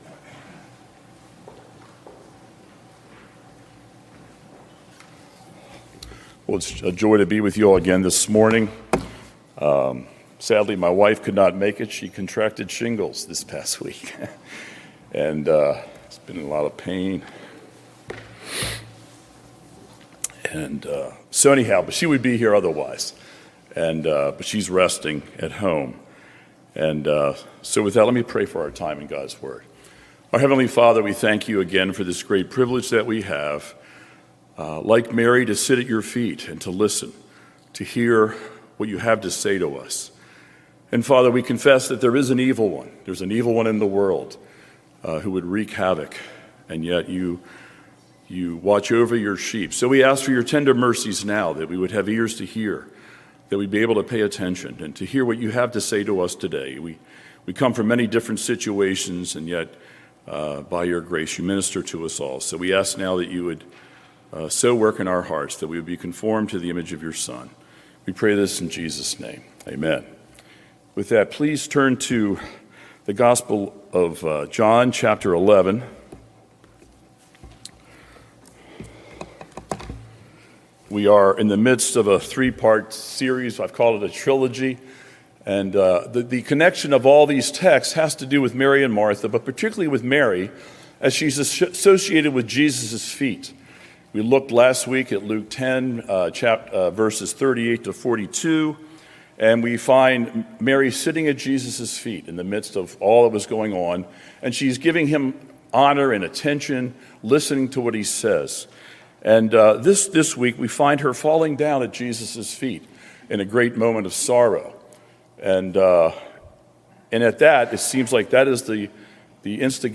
Hopes Dashed, Hopes Exceeded: Sermon on John 11:1-44 - New Hope Presbyterian Church